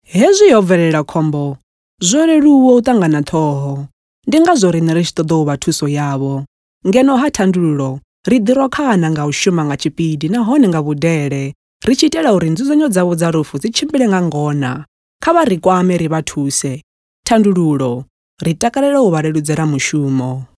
authentic, authoritative, bright, captivating, commercial, empathic, resonant, soothing
With a naturally warm and articulate tone, she delivers everything from soulful narration to vibrant commercial reads with clarity and purpose.
TshivendaVO-com.mp3